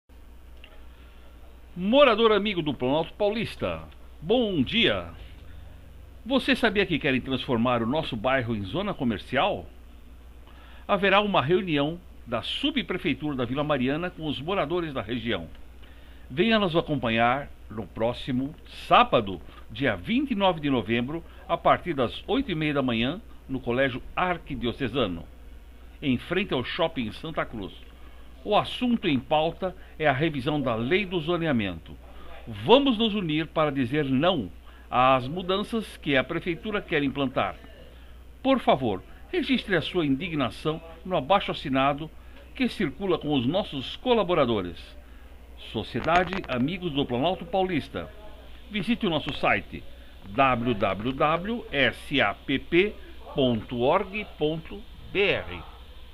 A Sapp através da ação de seus colaboradores organizaram uma passeata neste domingo das 10h às 13h, contemplando o quadrilátero Guaizes x Irerê x Tacaúnas x Itacira x Guaizes.
Sapp. audio da passeata
Sapp.-audio-da-passeata.wma